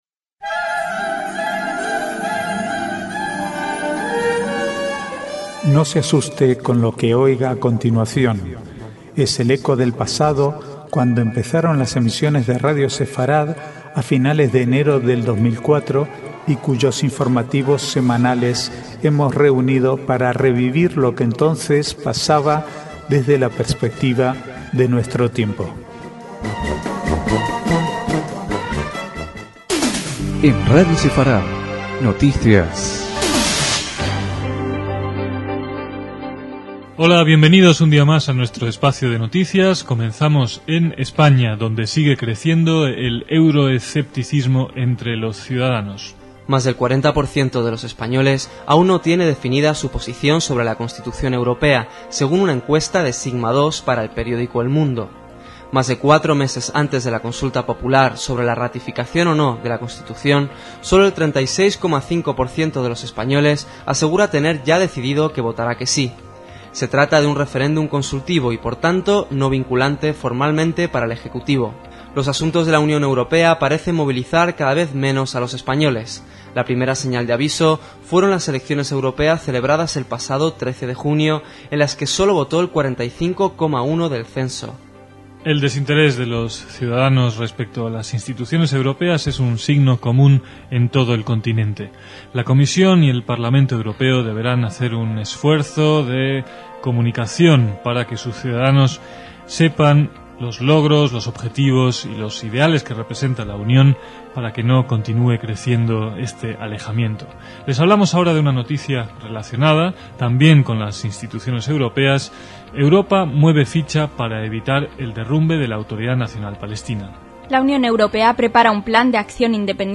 Archivo de noticias del 13 al 15/10/2004